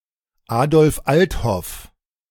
Adolf Althoff (German: [ˈaːdɔlf ˈʔalthɔf]